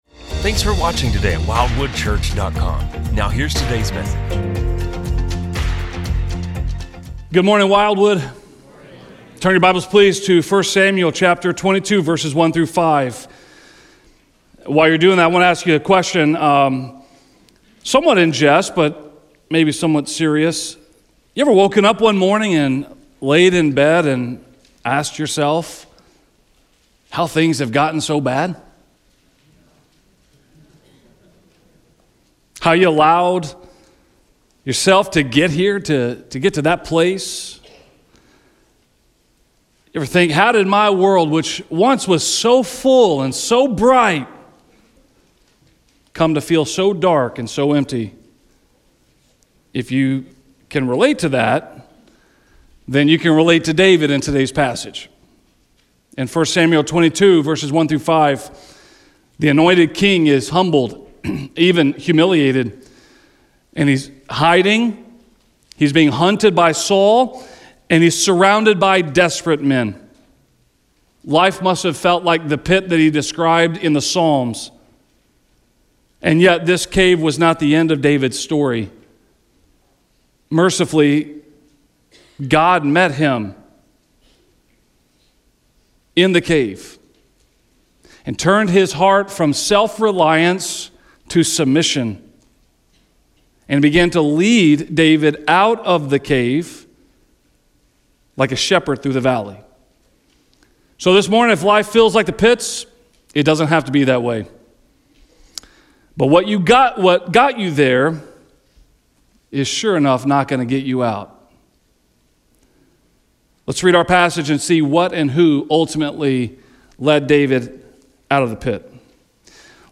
Yet even there, God meets him, restores his trust, and guides him forward. This sermon explores how God leads His people not by removing danger, but by walking with us through it.